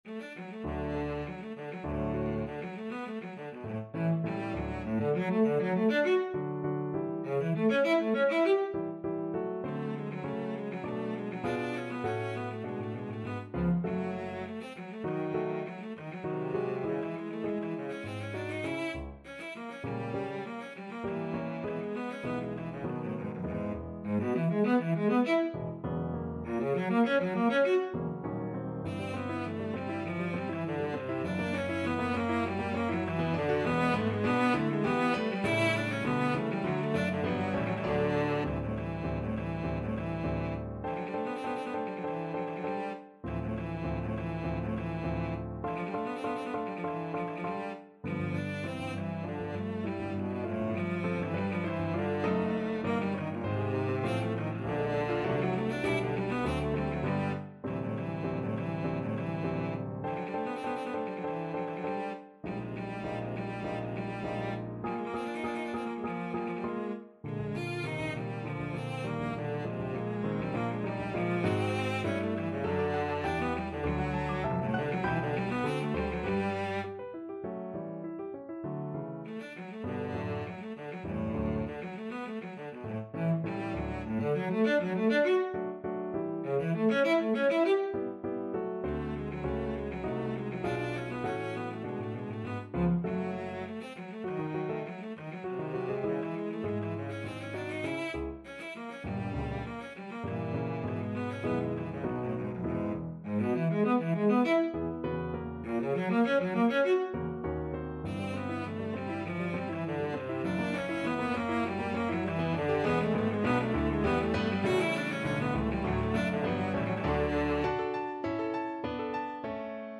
Cello version
2/2 (View more 2/2 Music)
~ = 200 Allegro Animato (View more music marked Allegro)
Classical (View more Classical Cello Music)